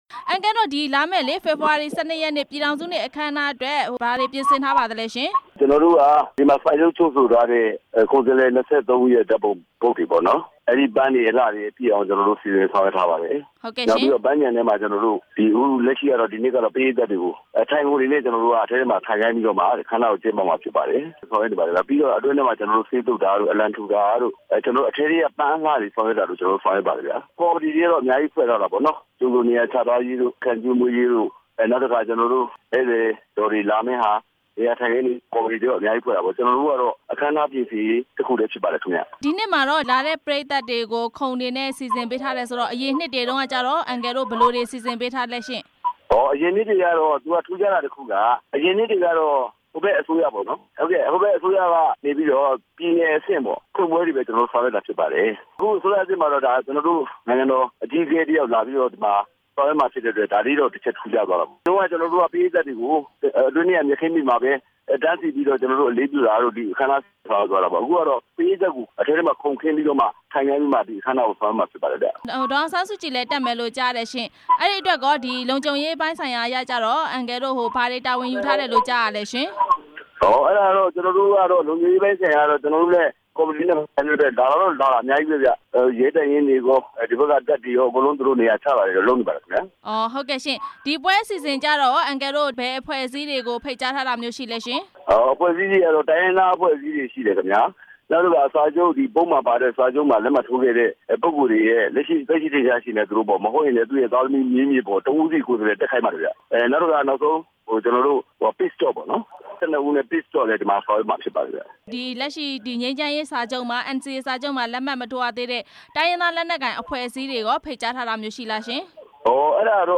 ပင်လုံမှာကျင်းပမယ့် ပြည်ထောင်စုနေ့အကြောင်း မေးမြန်းချက်